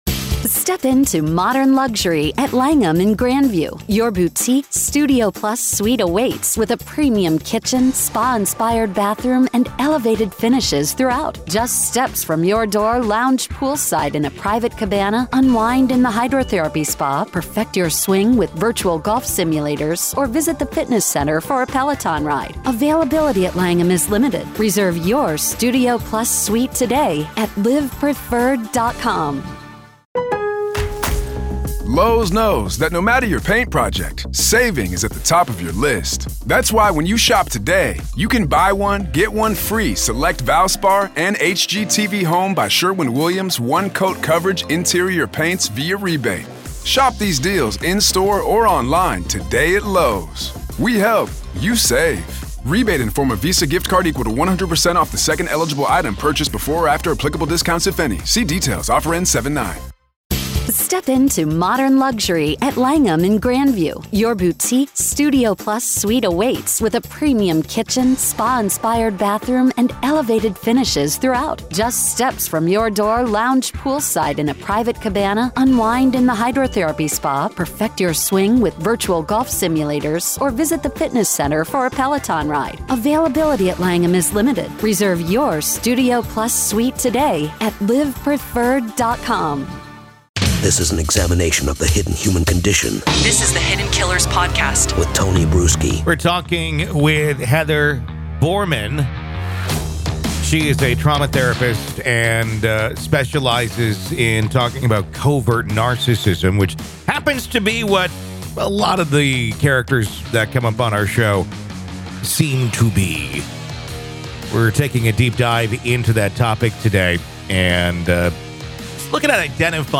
thought-provoking discussion